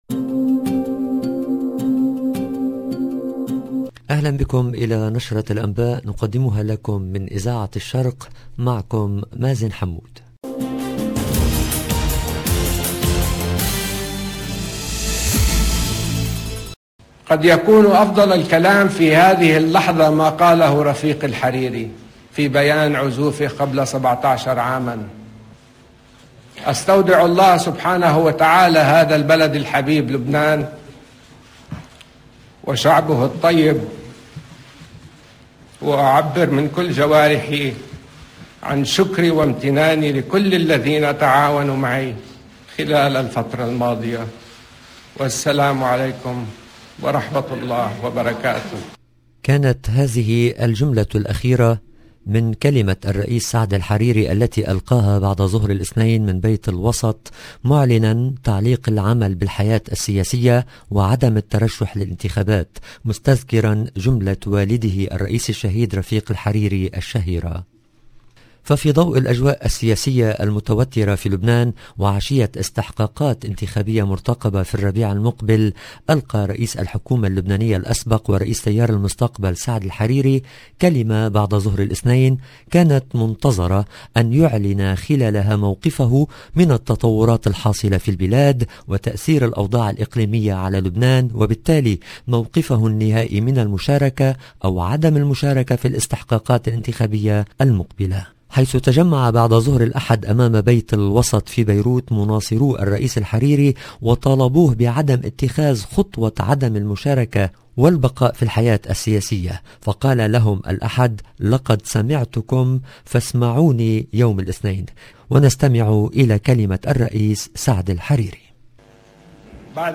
LE JOURNAL DU SOIR EN LANGUE ARABE DU 24/01/22